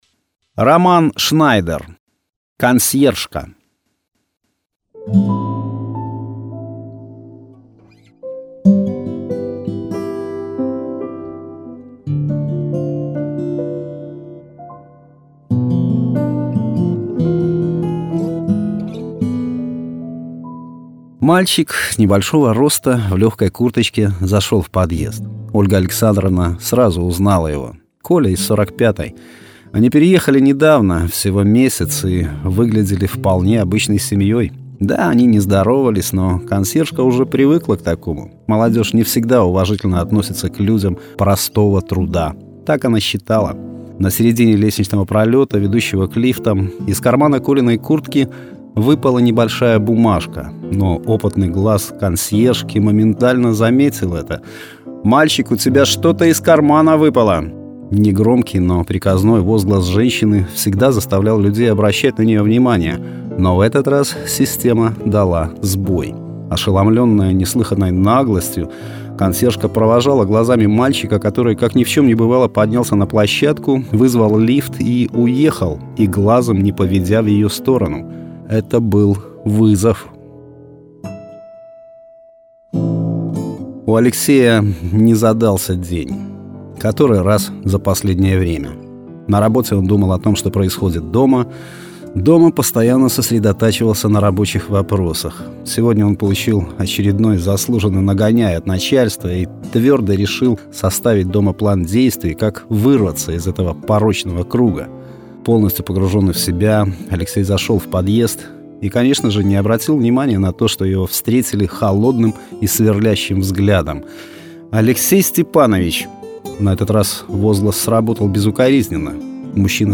Аудиорассказ
Качество: mp3, 256 kbps, 44100 kHz, Stereo